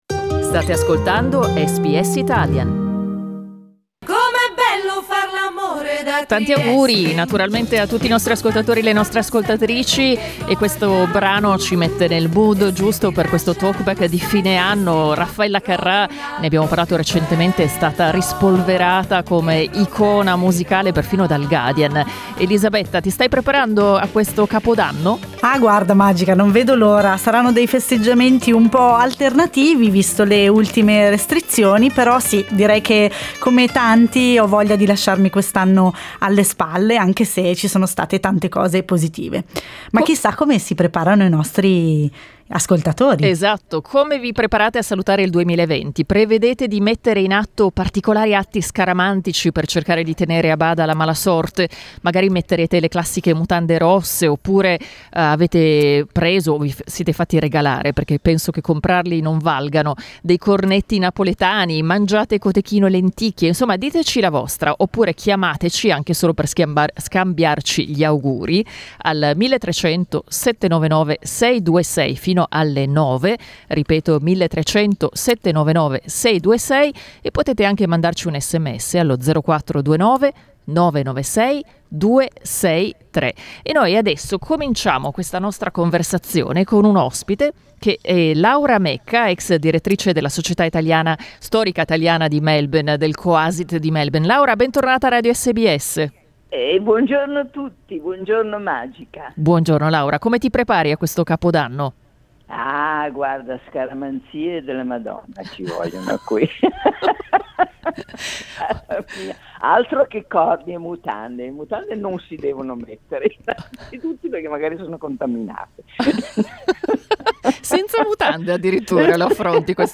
In questo 31 dicembre SBS Italian ha aperto le linee alla comunità italiana, per sentire i programmi e i buoni propositi di fine 2020.